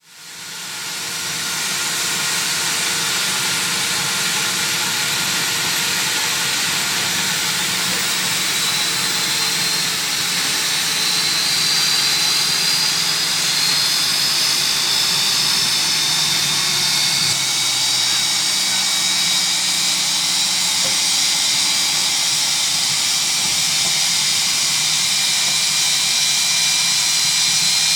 v2500-starter.wav